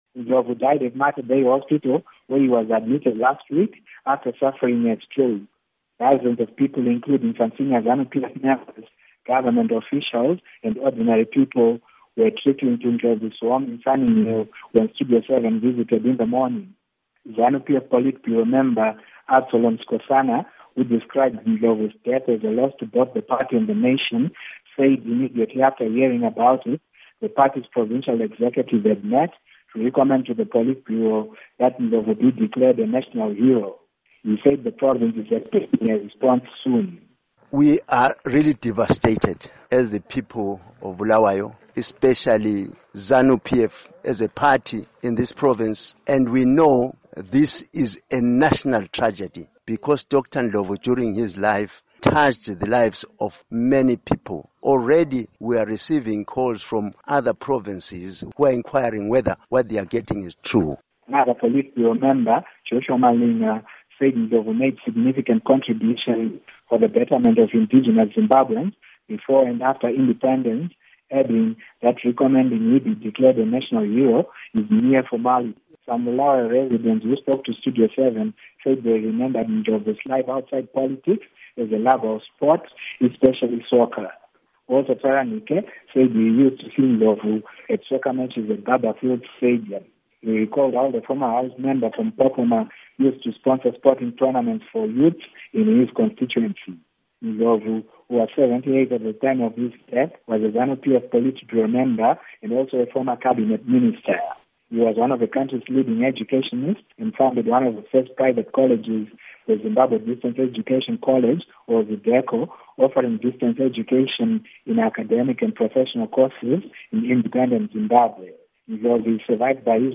Report on Sikhanyiso Ndlovu's Death